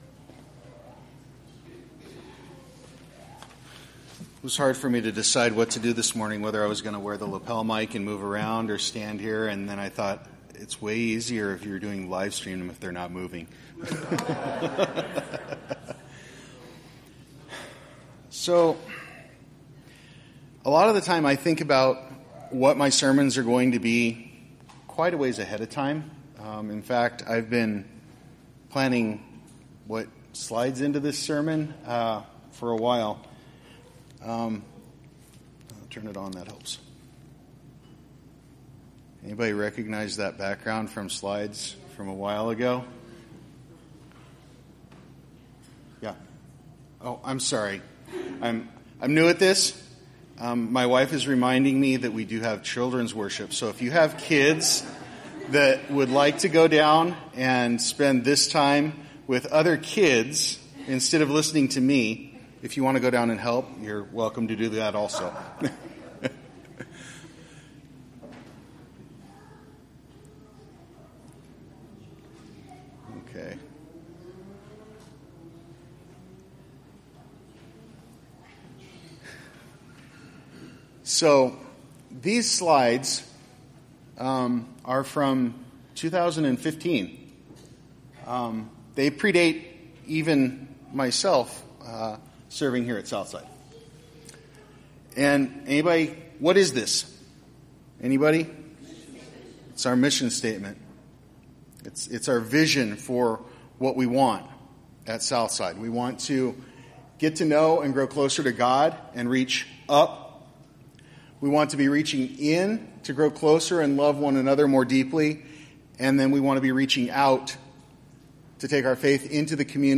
Sermon-Audio-September-3-2023.mp3